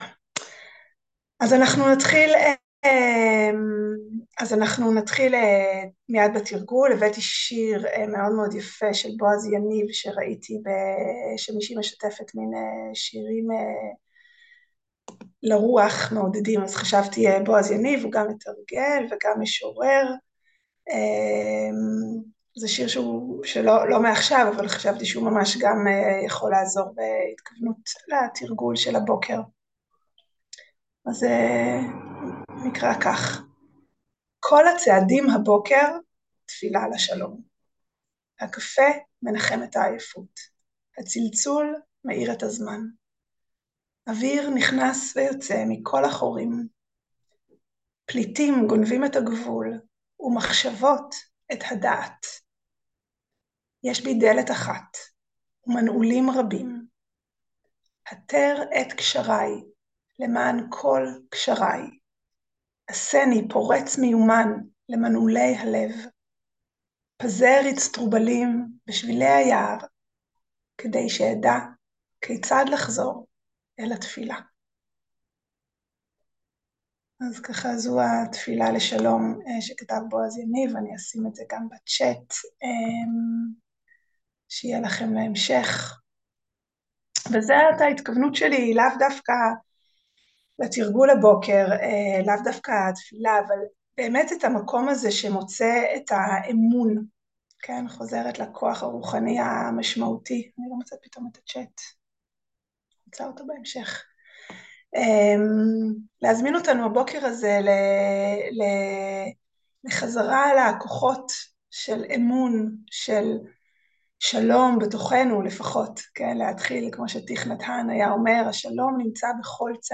21.10.2023 - מרחב בטוח - תרגול נשימה וגוף ושיחה קצרה על חמשת הכוחות הרוחניים
Dharma talk